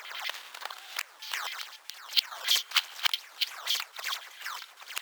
15 Harsh Realm Effect 3.wav